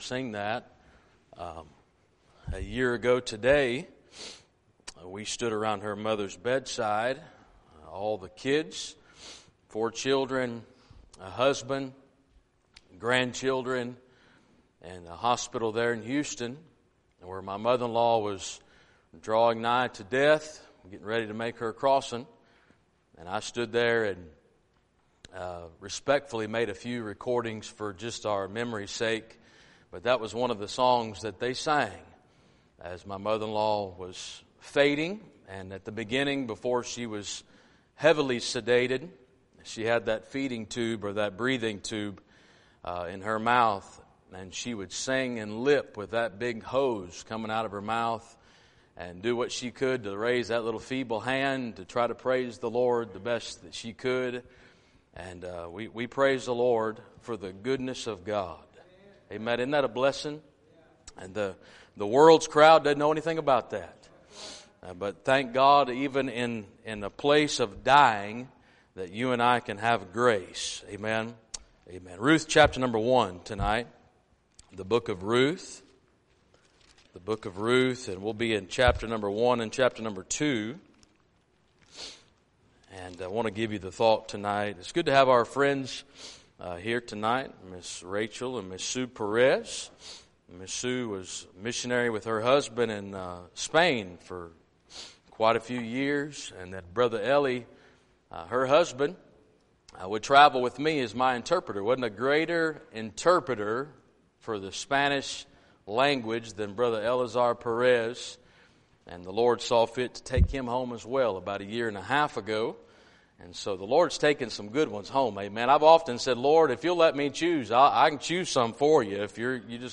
Topic Sermons